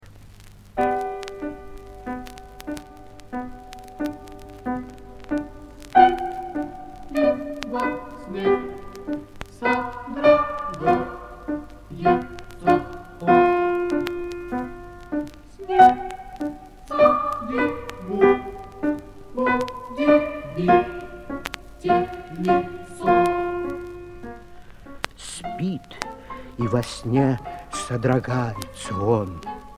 Инструментальный ансамбль